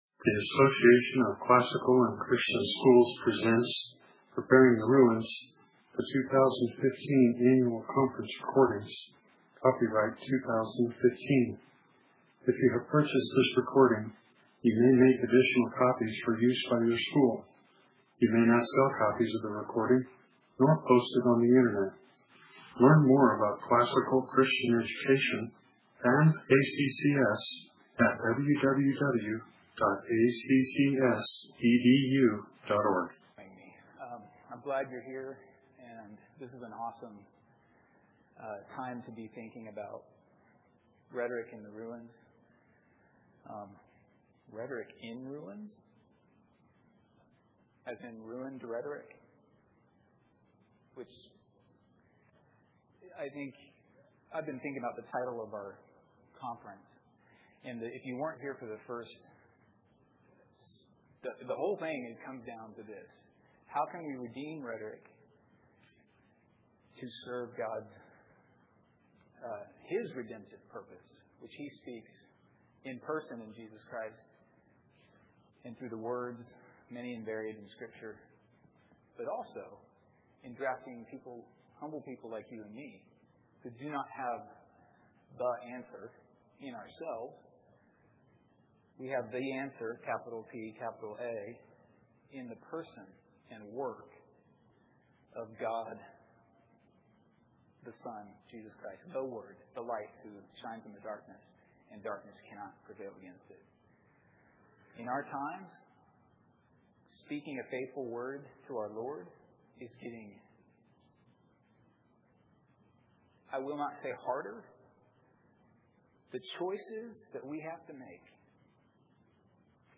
2015 Workshop Talk | 0:59:39 | 7-12, Rhetoric & Composition